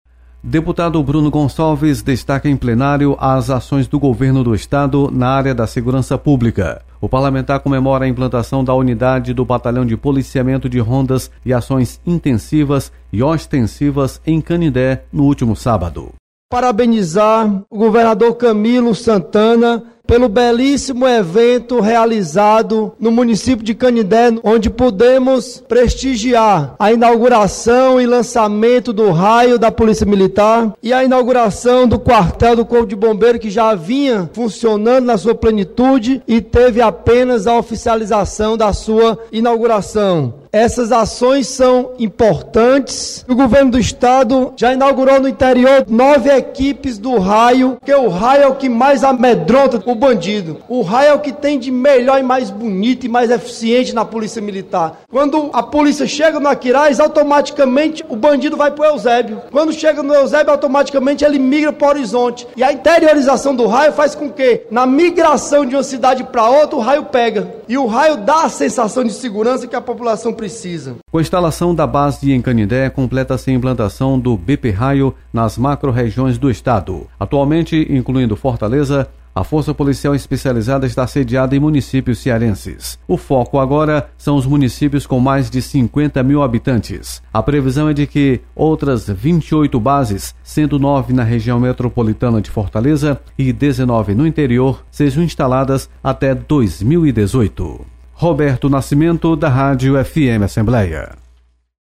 Deputado Bruno Gonçalves comemora implantação de batalhão do Raio em Canindé. Repórter